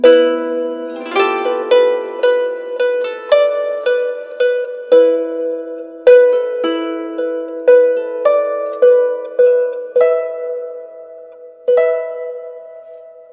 Harp07_108_G.wav